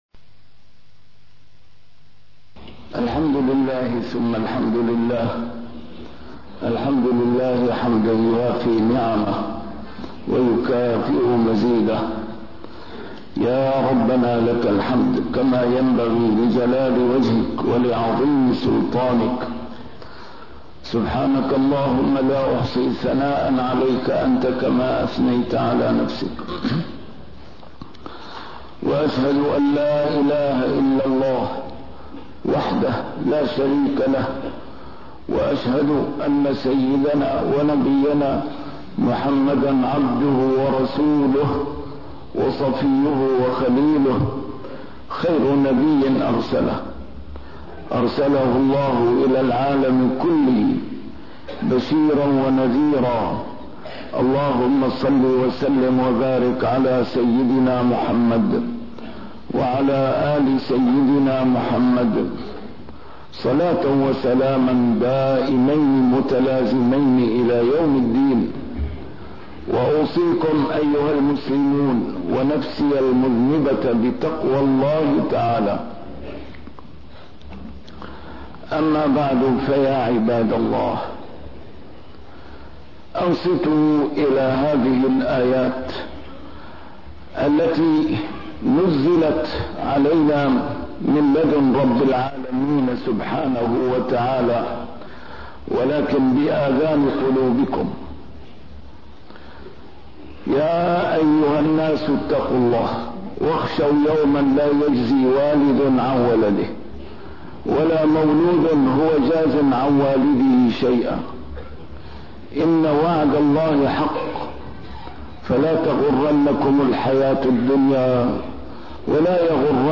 A MARTYR SCHOLAR: IMAM MUHAMMAD SAEED RAMADAN AL-BOUTI - الخطب - رب ارجعون لعلي أعمل صالحاً